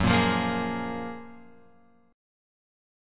F# Major 9th